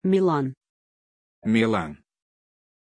Aussprache von Milàn
pronunciation-milàn-ru.mp3